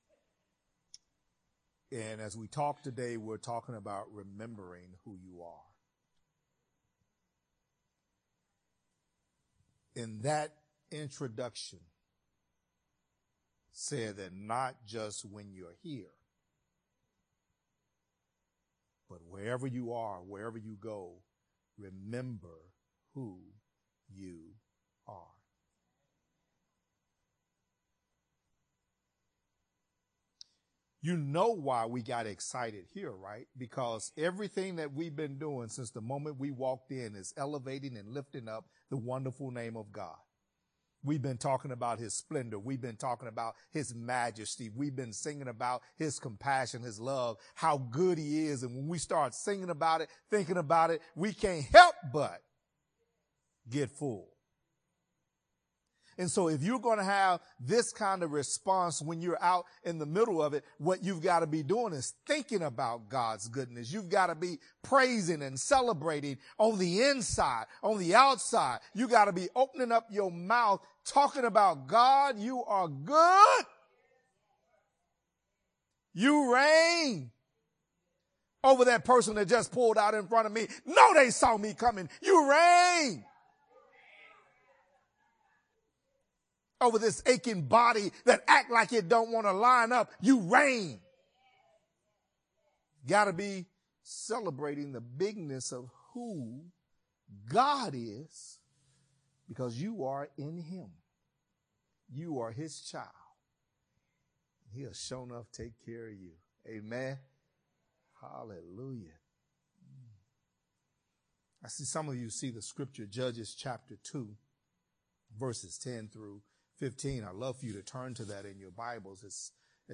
Sermons | New Joy Fellowship Ministry